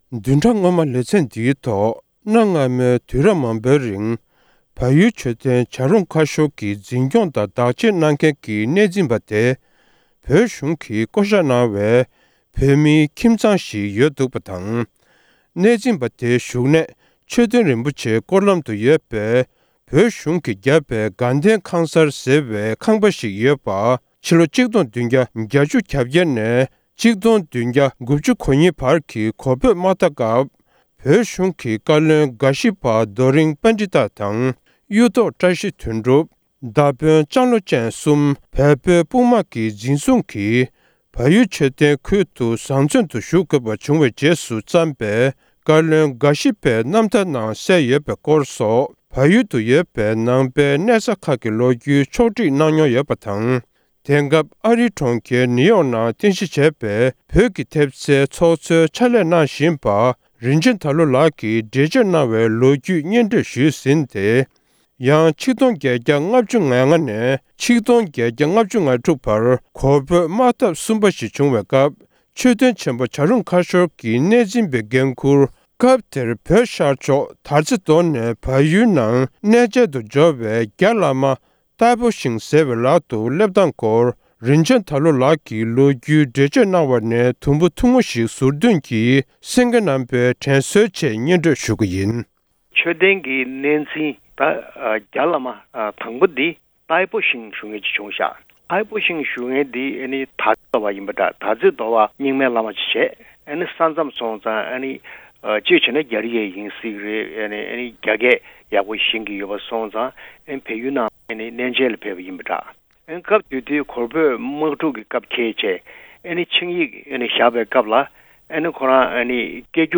བལ་ཡུལ་མཆོད་རྟེན་གྱི་གནས་འཛིན་པ་བོད་པས་བསྐོ་གཞག ལེ་ཚན་གཉིས་པ། སྒྲ་ལྡན་གསར་འགྱུར།